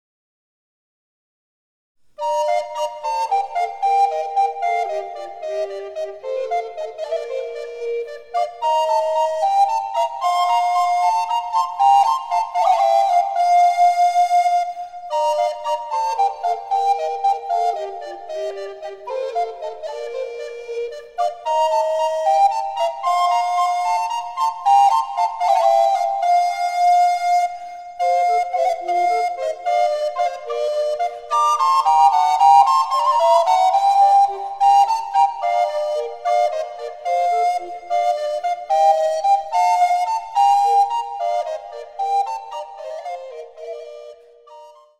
第７組曲　変ロ長調
第８組曲　ハ短調
・演奏例